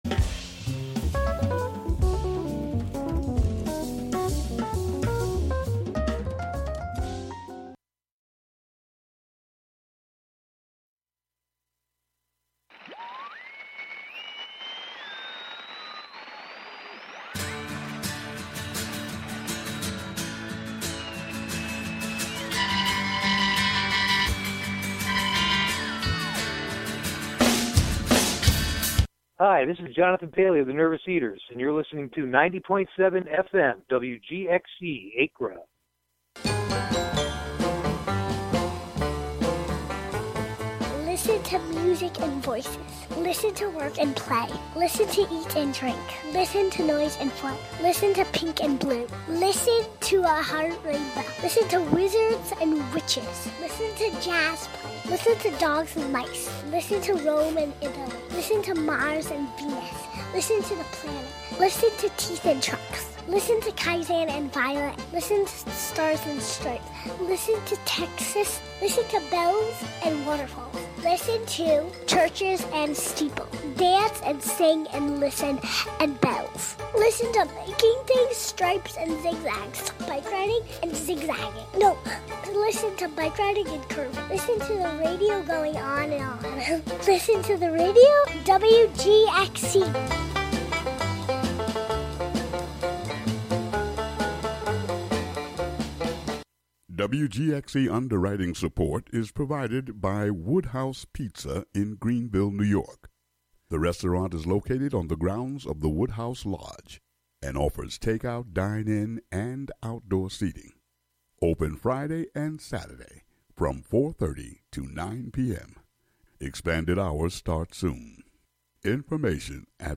An upbeat music show featuring the American songbook, as interpreted by contemporary artists as well as the jazz greats in a diverse range of genres from Hudson, New York. Spring is the theme today.